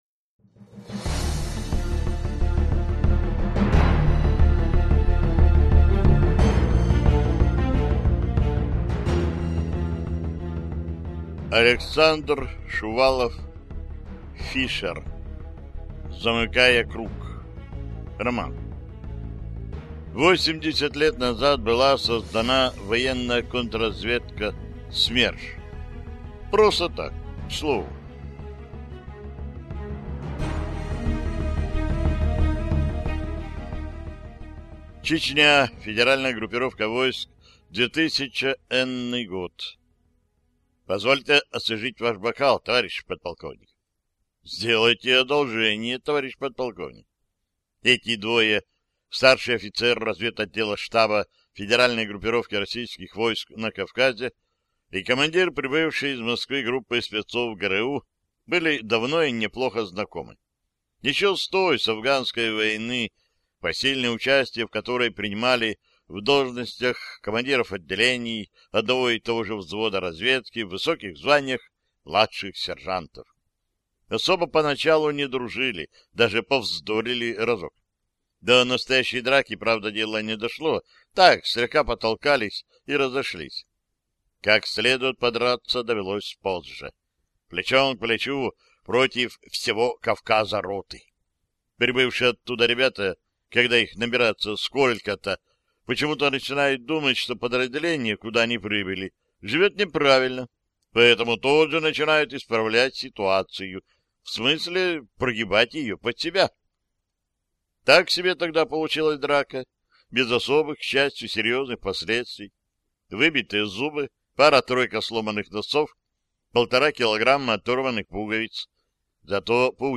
Аудиокнига Фишер. Замыкая круг | Библиотека аудиокниг